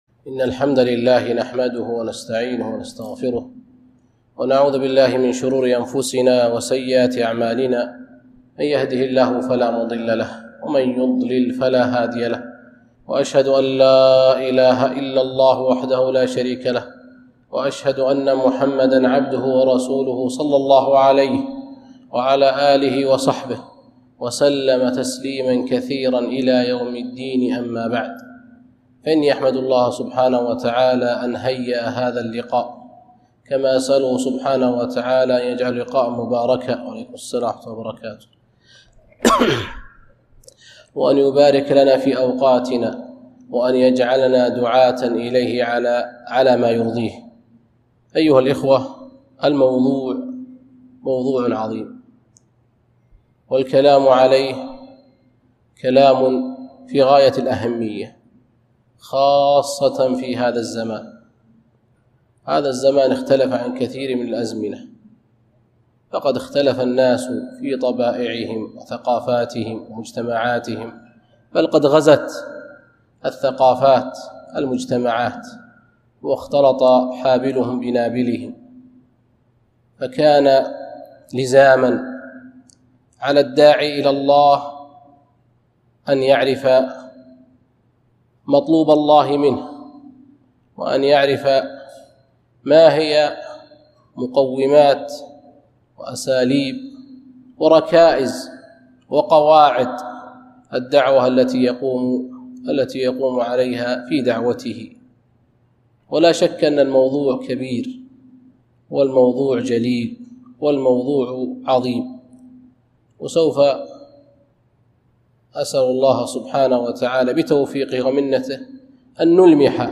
محاضرة - مقومات الداعية الناجح